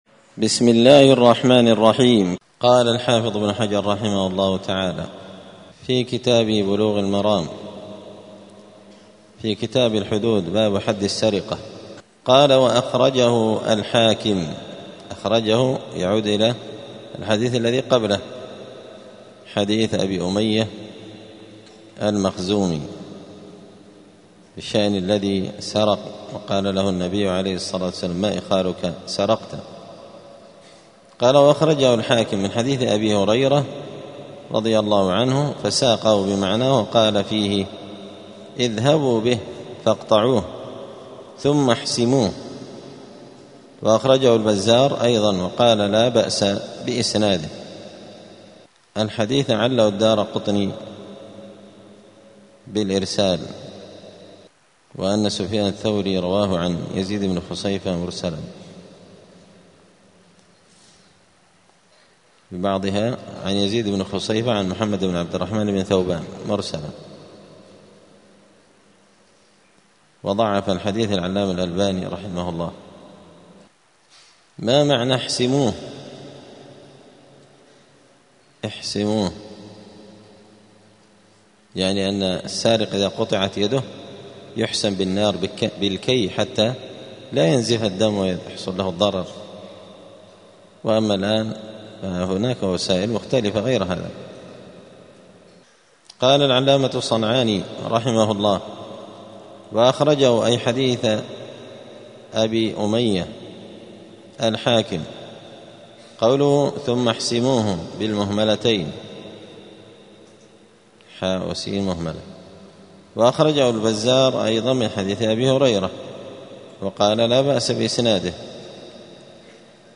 *الدرس الخامس والعشرون (25) {باب حد السرقة حسم ما قطع بالسرقة}*
دار الحديث السلفية بمسجد الفرقان قشن المهرة اليمن